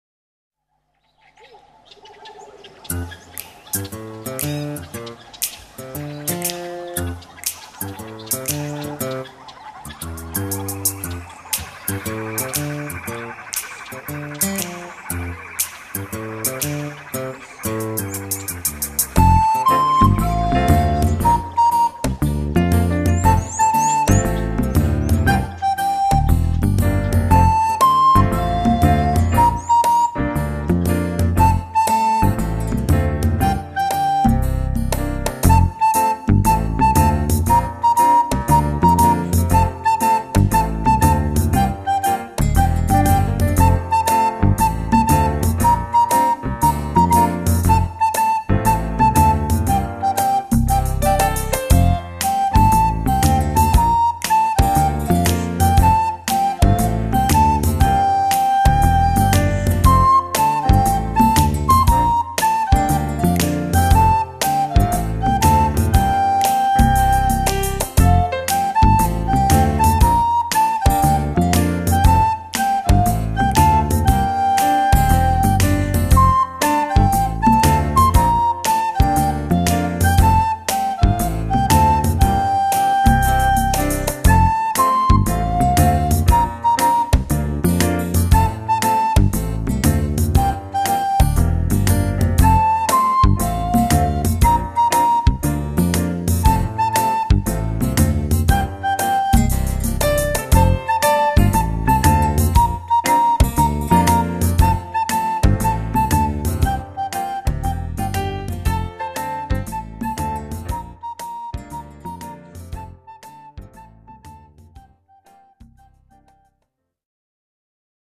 Flauta 6è- Wimoweh